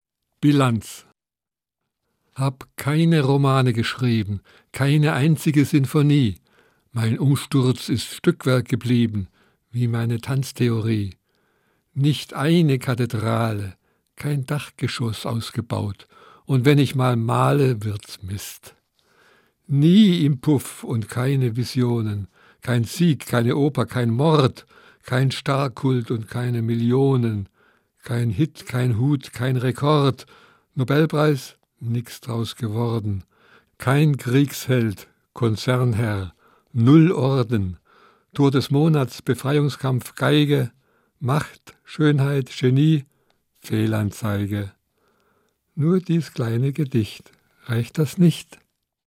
Das radio3-Gedicht der Woche: Dichter von heute lesen radiophone Lyrik.
Gelesen von F.W. Bernstein.